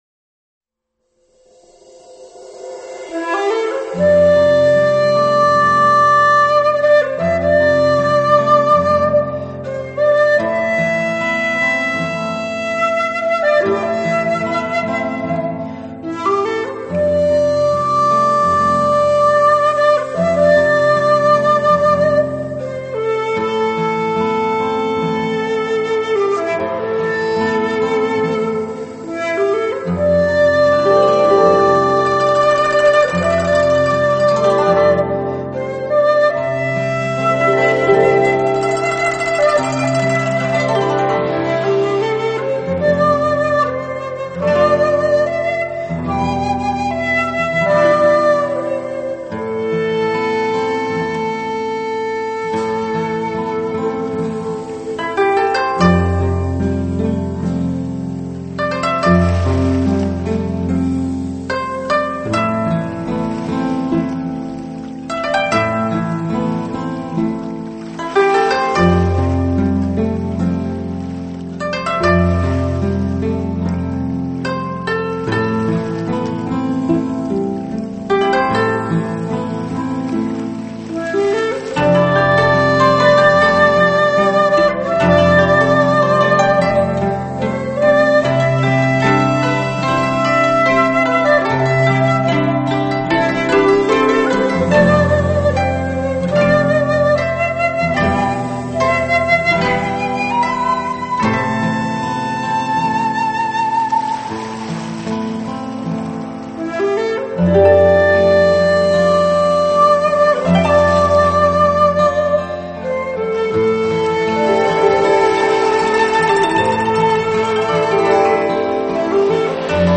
唱片類型：New Age